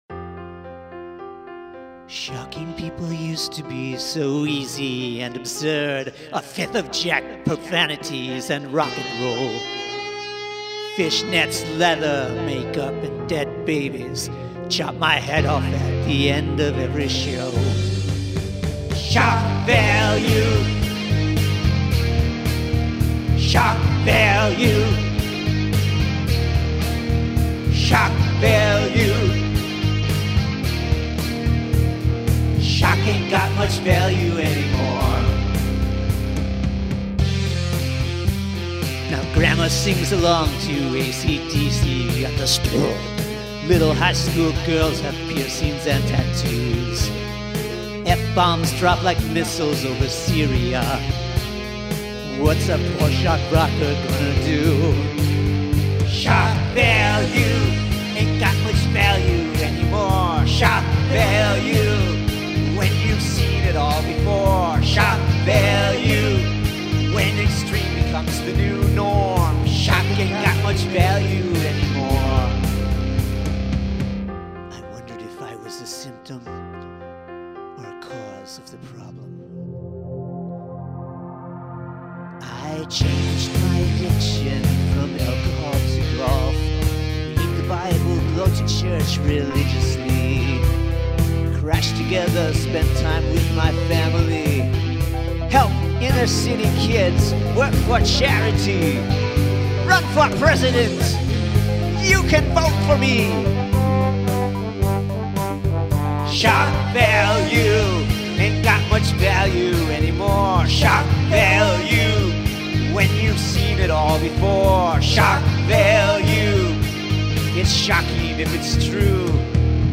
I like the chorus melody.
Has dynamics.